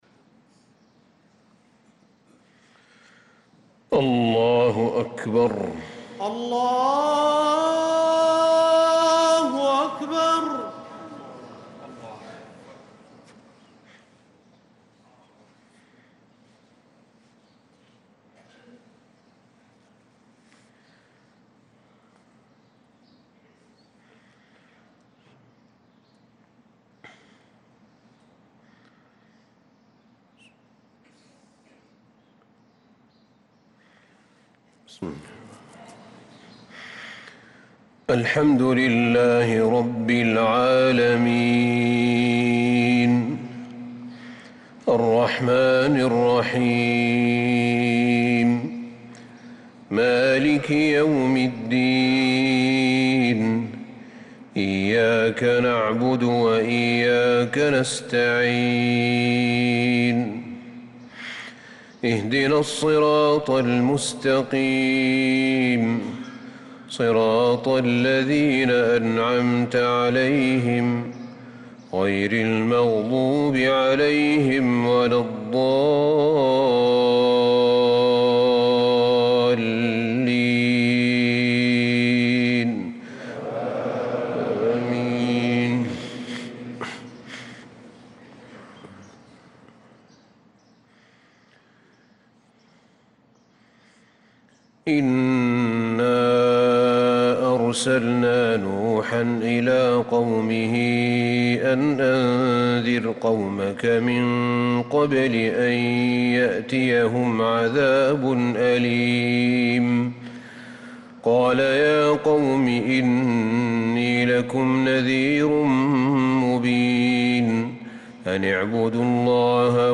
صلاة الفجر للقارئ أحمد بن طالب حميد 26 ربيع الأول 1446 هـ
تِلَاوَات الْحَرَمَيْن .